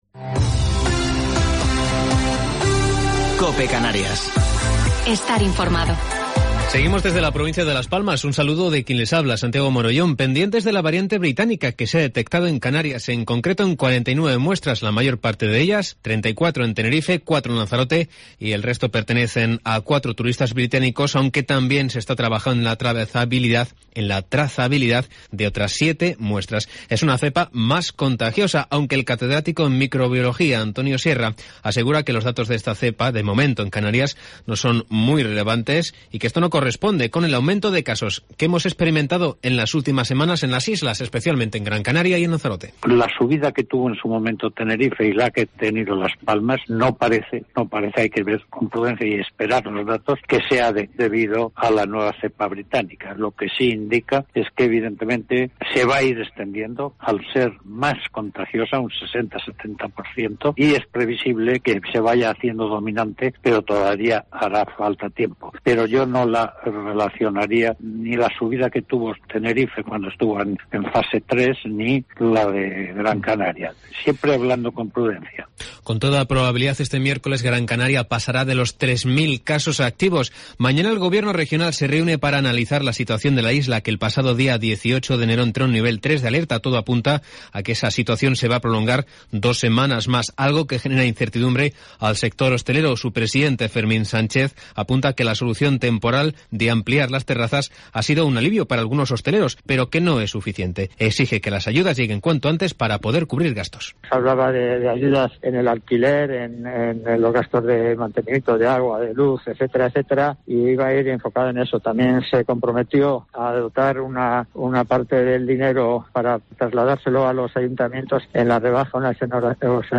Informativo local 27 de Enero del 2021